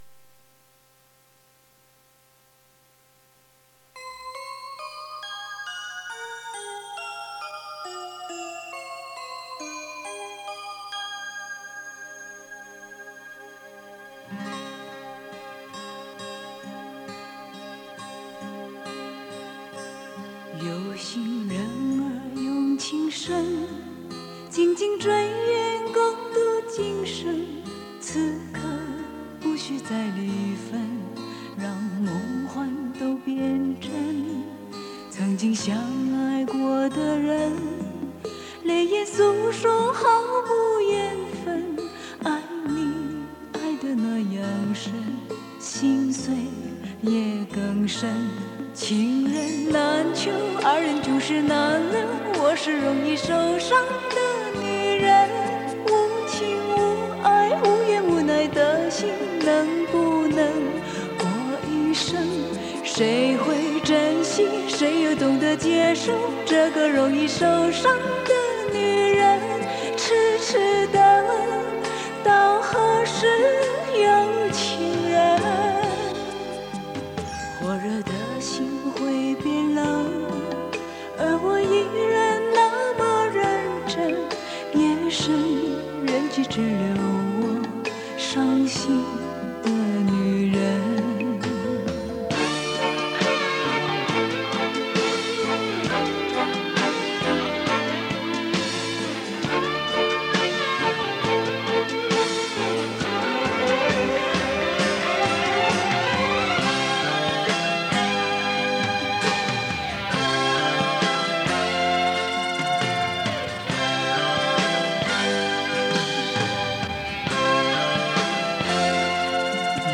磁带数字化：2022-07-21
国语版 风情万种